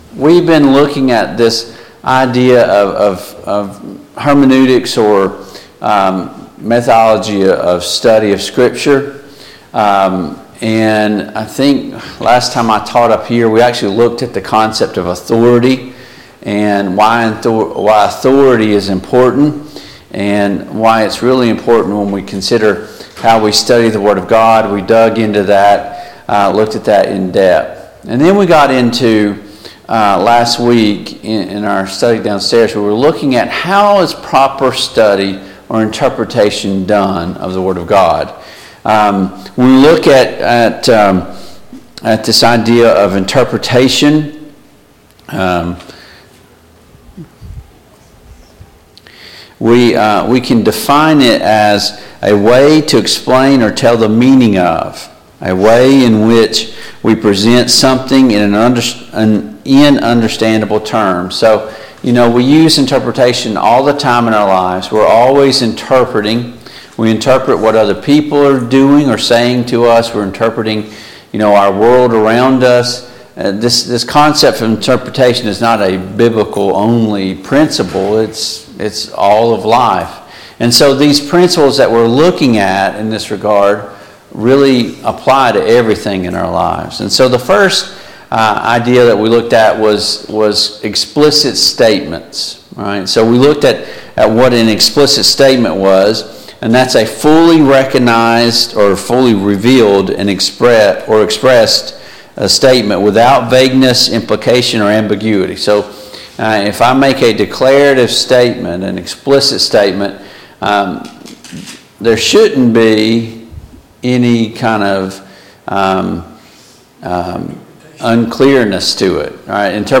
Christian Foundations Service Type: Sunday Morning Bible Class Download Files Notes Topics: Biblical Interpretation « 12.